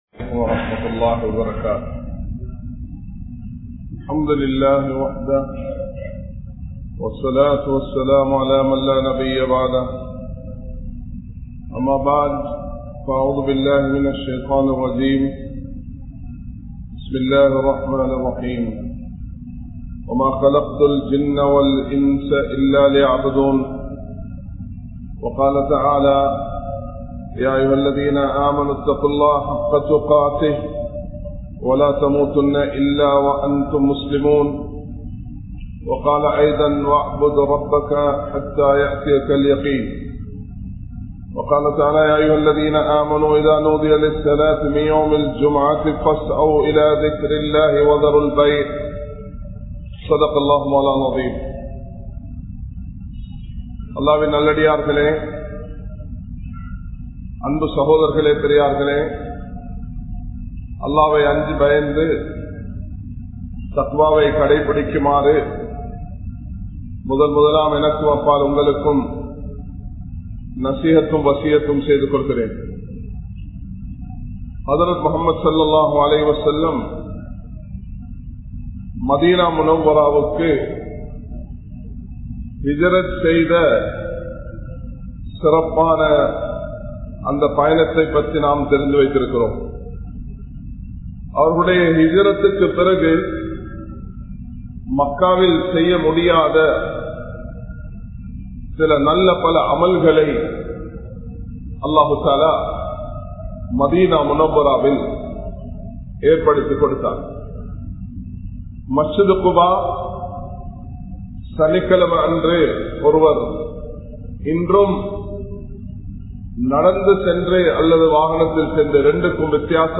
Importance Of Jumuah | Audio Bayans | All Ceylon Muslim Youth Community | Addalaichenai
Colombo 03, Kollupitty Jumua Masjith